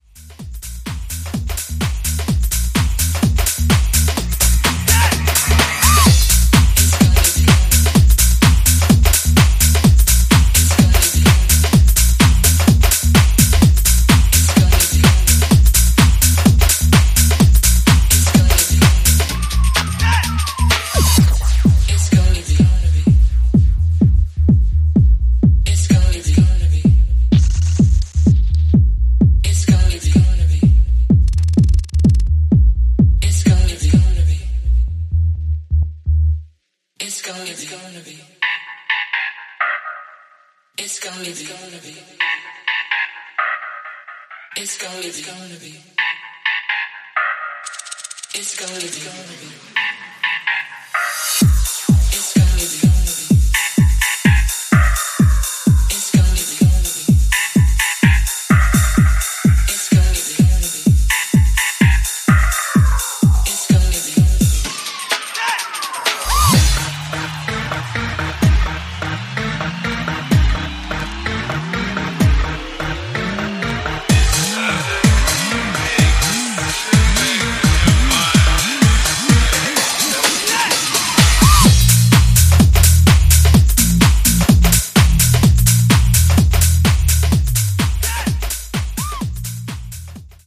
ジャンル(スタイル) HOUSE / DEEP TECH